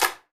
ECLAP 1.wav